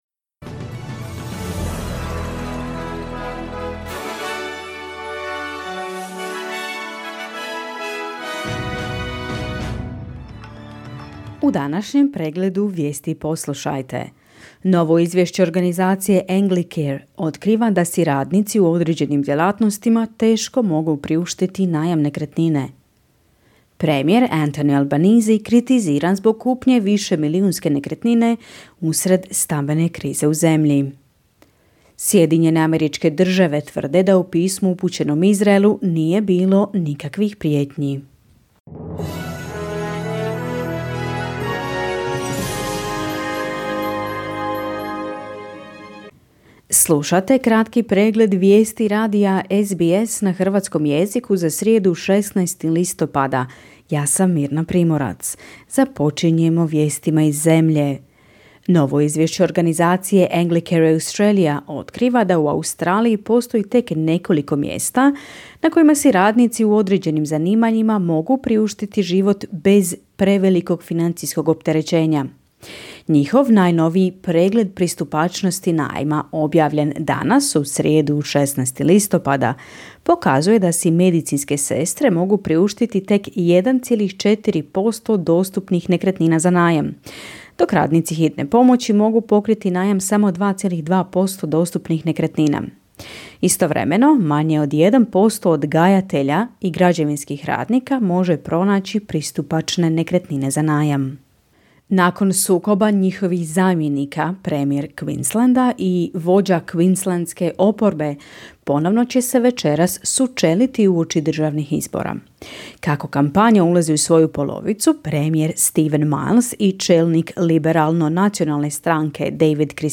Kratke vijesti SBS-a na hrvatskom jeziku.
Vijesti radija SBS.